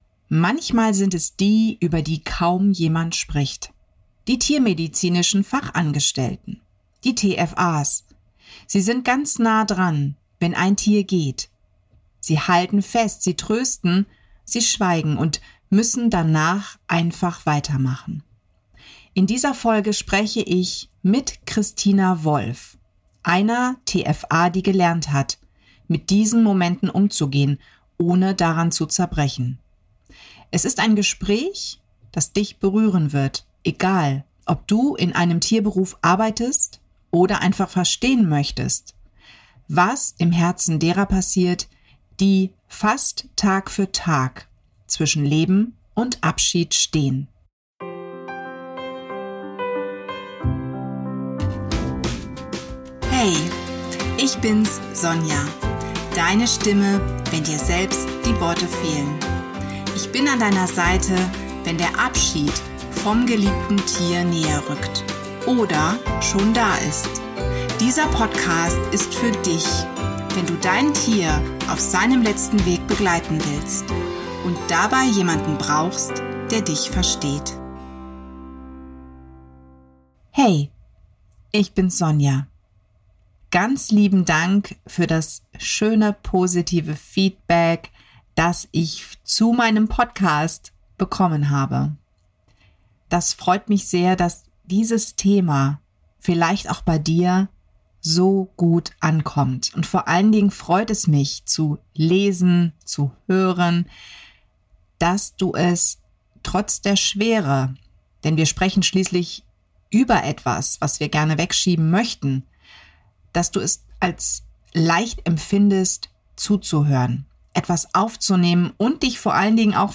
In dieser besonderen Episode der Lebewohlpfote gibt es ein Interview. Ich spreche mit einer tiermedizinischen Fachangestellten über ein Thema, das selten sichtbar ist und doch jeden Abschied begleitet: Euthanasie beim Tierarzt und die Rolle der TFA.